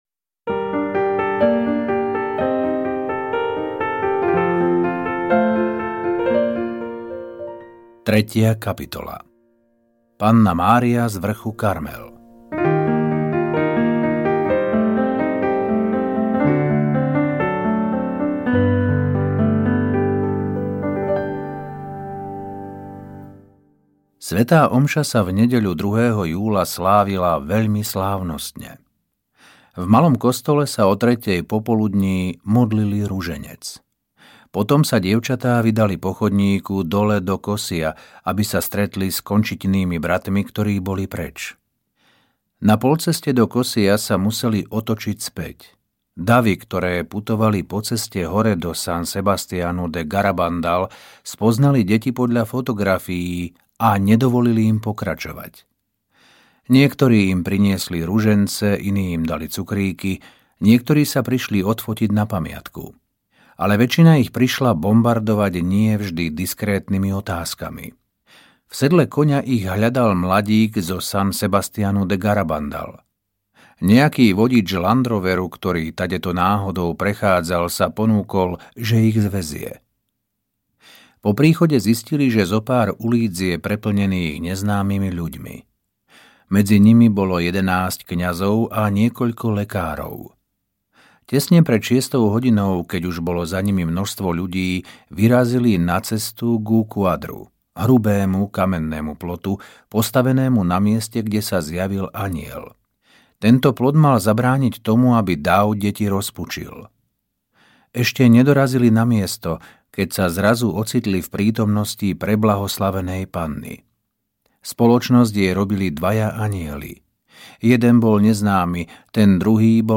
Garabandal audiokniha
Ukázka z knihy